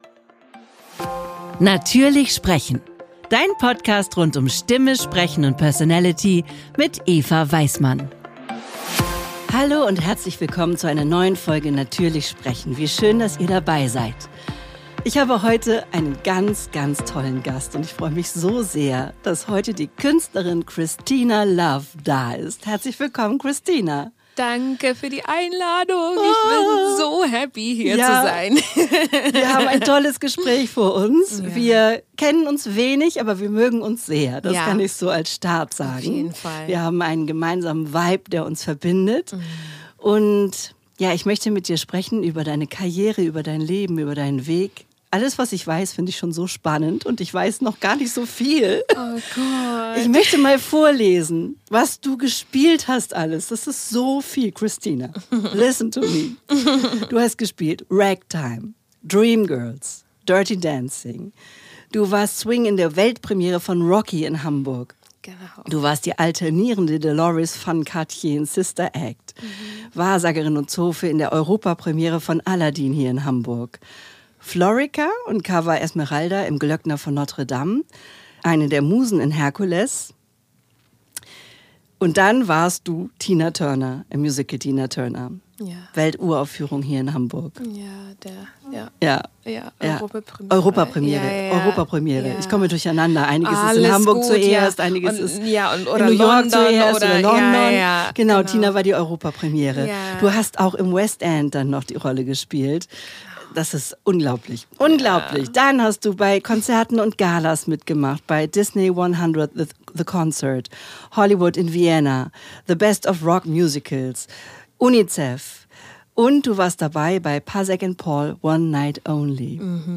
Dieses Gespräch hat mich selber sehr berührt.